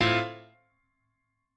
admin-leaf-alice-in-misanthrope/piano34_6_006.ogg at main